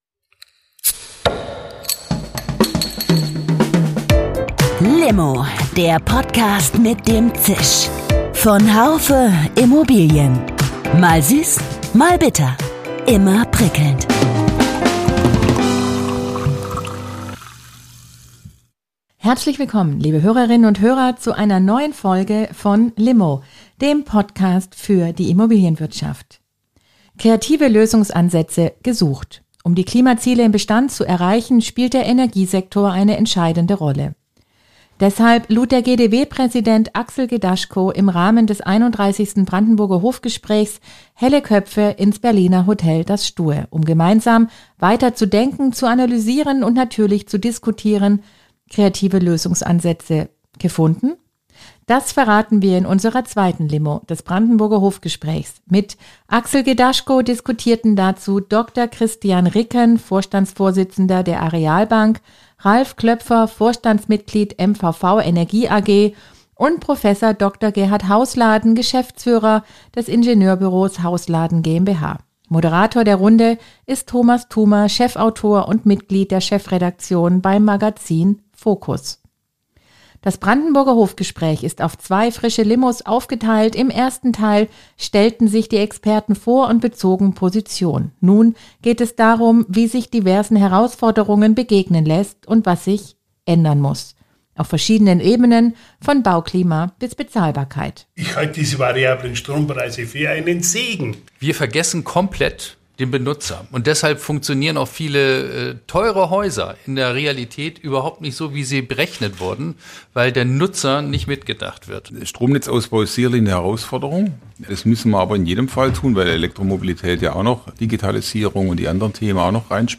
Beschreibung vor 1 Jahr Kreative Lösungsansätze gesucht: Um die Klimaziele im Bestand zu erreichen, spielt der Energiesektor eine entscheidende Rolle. Im Rahmen des 31. Brandenburger Hof Gesprächs